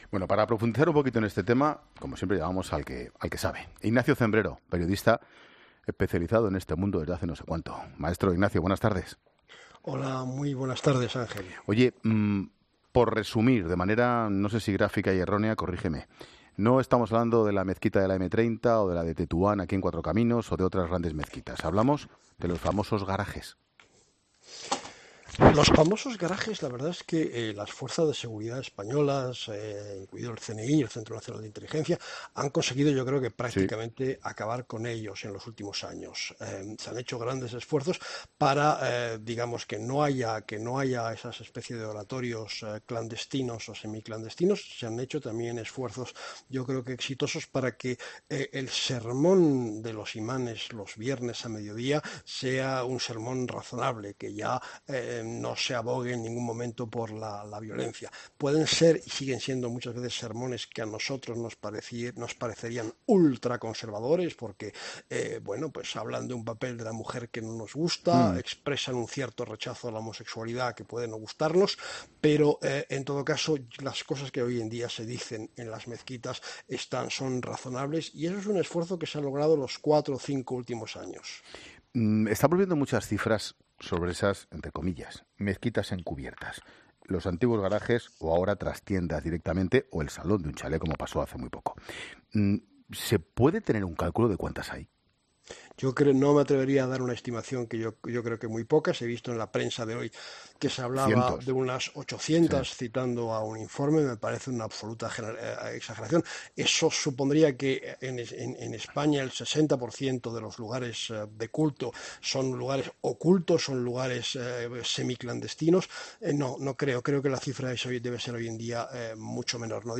Ignacio Cembrero, experto en el mundo árabe, ha explicado en 'La Tarde' que en España “hay muchos esfuerzos” para que no vuelva a ocurrir un atentado como el de Barcelona el pasado 17 de agosto en el que fallecieron 16 personas tras el ataque en Las Ramblas.